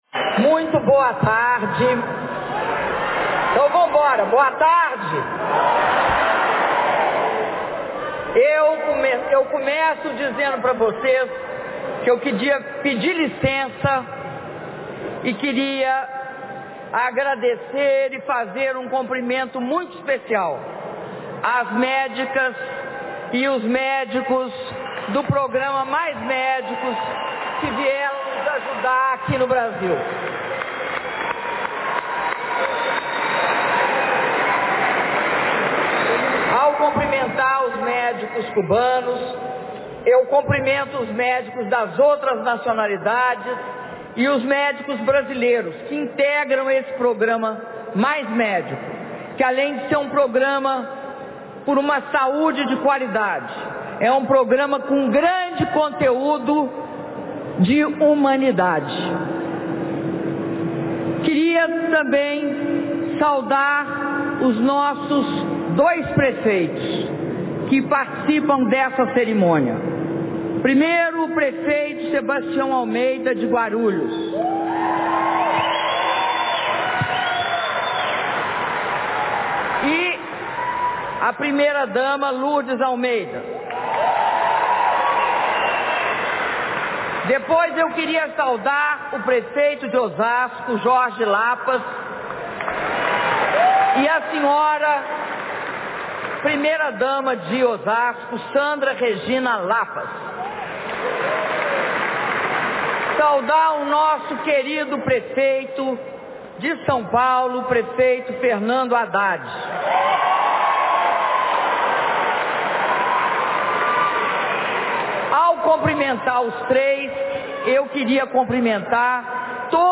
Áudio do discurso da Presidenta da República, Dilma Rousseff, na cerimônia de anúncio de investimentos do PAC2 Mobilidade Urbana para Guarulhos e Osasco - Guarulhos/SP
Discurso da Presidenta Dilma Rousseff na cerimônia de anúncio de investimentos do PAC2 Mobilidade Urbana para Guarulhos e Osasco - Guarulhos/SP